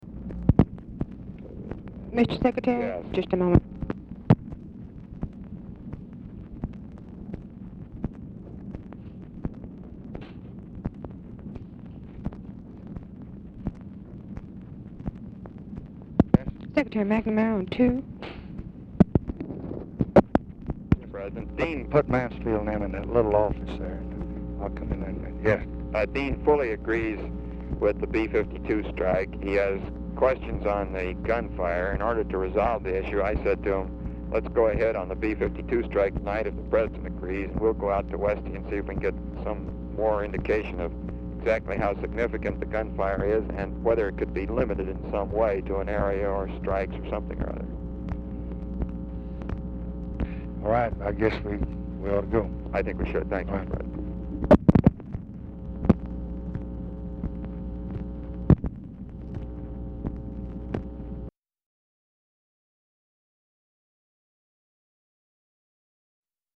OFFICE CONVERSATION
Oval Office or unknown location
Telephone conversation
Dictation belt